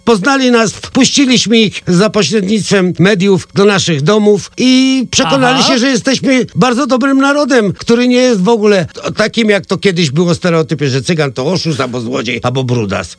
Na Festiwalu Weselnych Przebojów nie zabraknie także cygańskiej, romantycznej nuty prosto z romskiej rozśpiewanej duszy. Na koncert w studiu Radia Deon Chicago zaprasza Don Vasyl opowiadając o weselnych zwyczajach Romów.